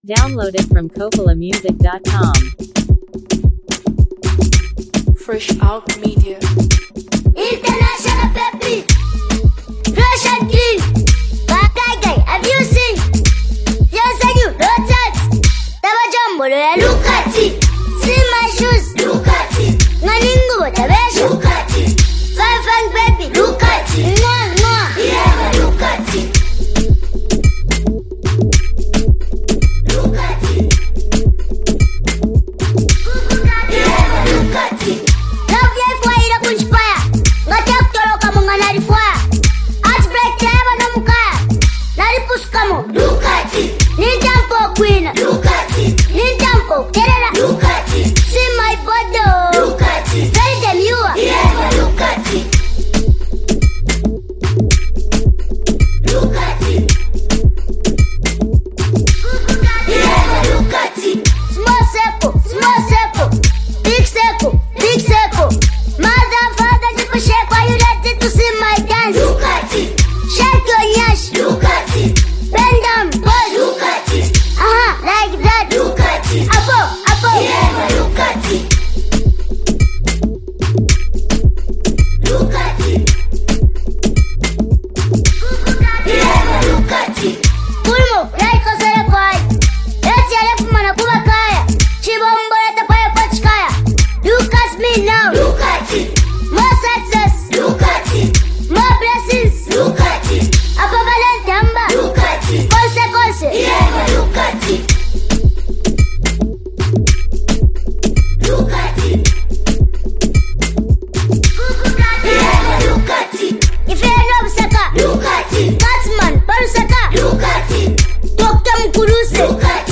a confident and stylish track